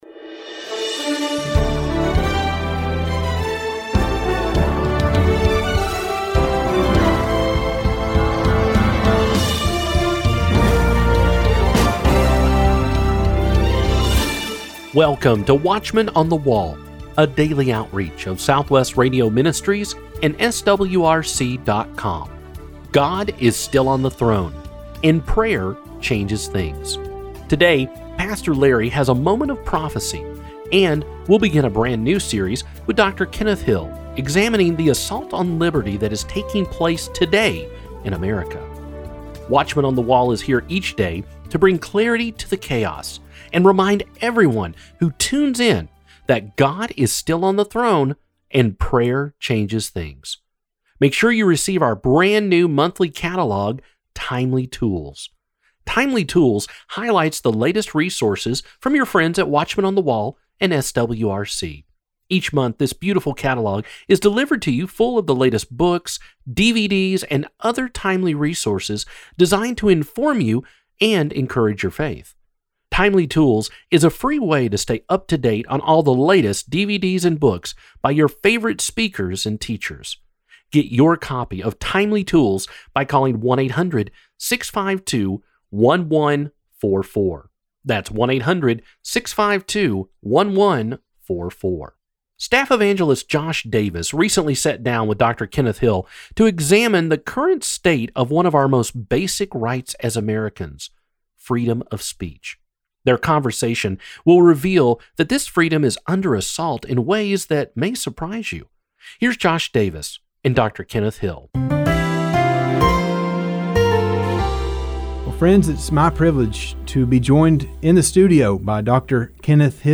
Recorded live at the Las Vegas Prophecy Conference. How the Mass Media Mesmerizes the Minds of the Masses seeks to inform, expose, and equip you with the shocking evidence of how our whole planet really is being controlled and manipulated by a small group of entities who are mesmerizing us for their own nefarious agendas using the power of Mass Media to get the job done.